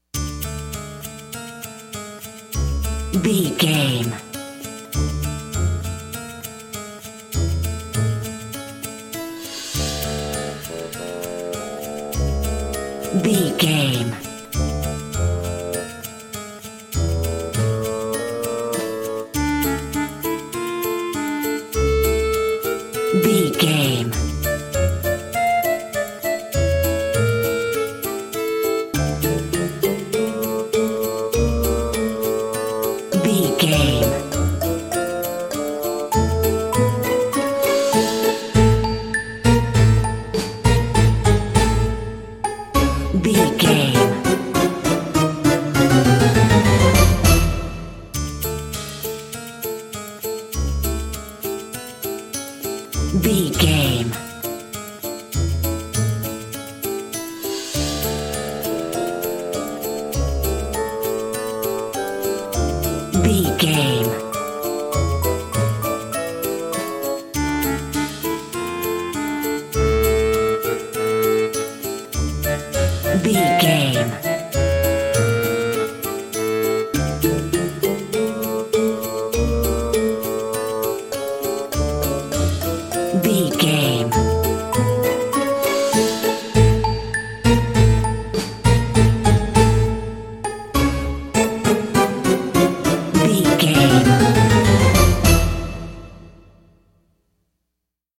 Aeolian/Minor
D
orchestra
harpsichord
silly
circus
goofy
comical
cheerful
perky
Light hearted
quirky